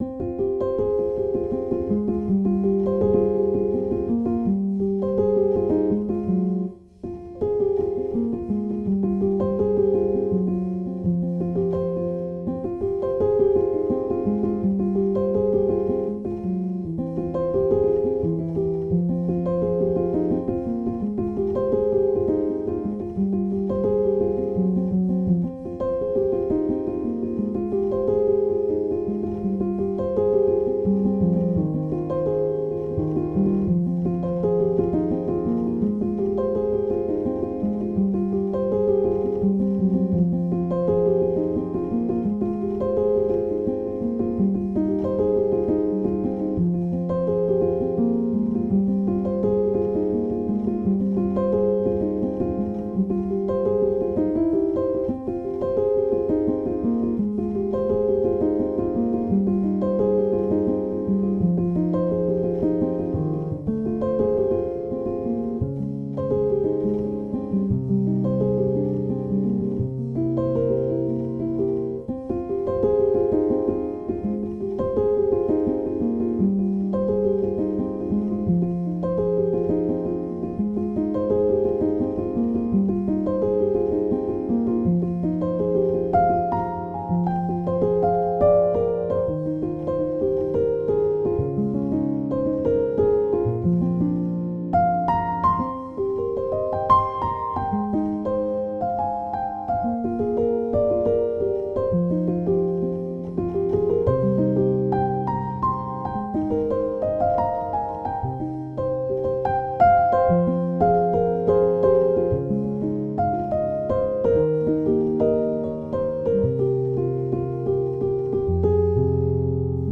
Anyway, I sat down this morning and just hit record, and let my fingers do the talking. This is my melancholy in music.